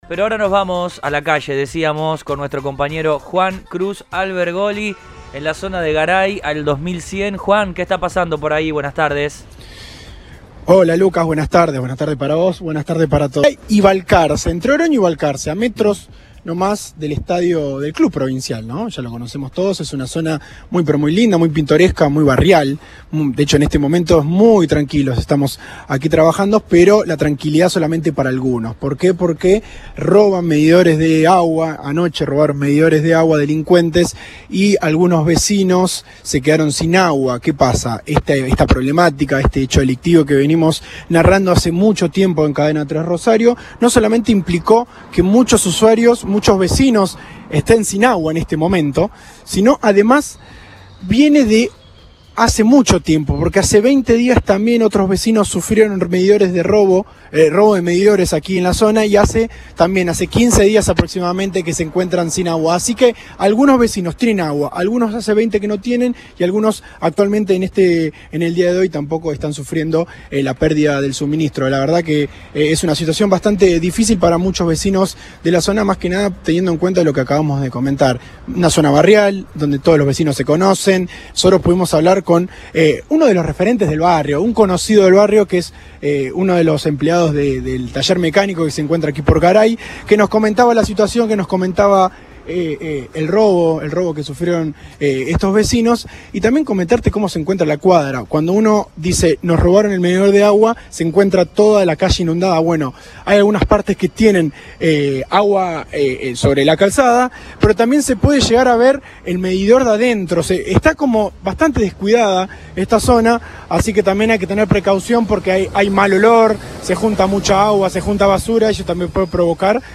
Sin embargo, el alcance de estos actos delictivos se extiende a las zonas cercanas, según denunciaron los vecinos ante el móvil de Cadena 3 Rosario.